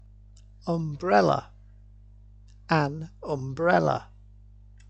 (um-brell-a)
um-brell-a.mp3